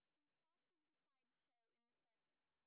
sp19_street_snr10.wav